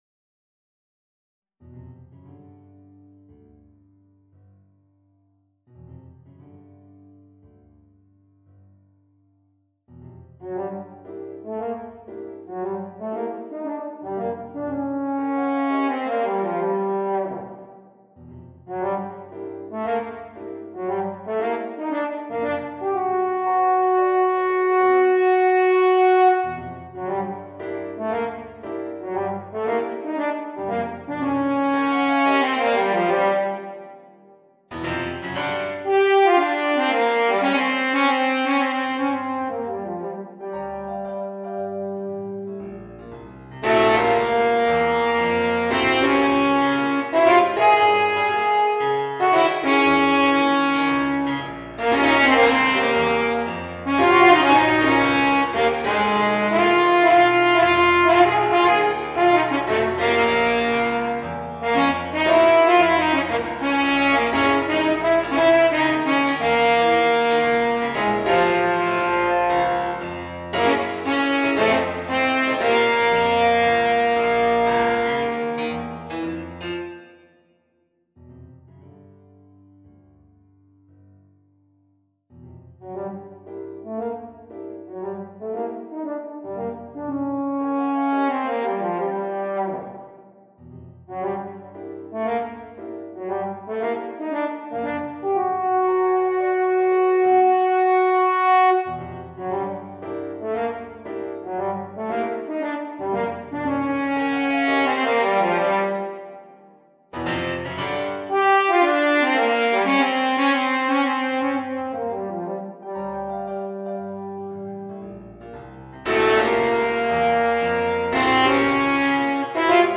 Voicing: French Horn and Piano